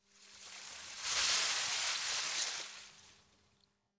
Leaves Rustling
A canopy of leaves rustling in a moderate breeze with layered movement and natural rhythm
leaves-rustling.mp3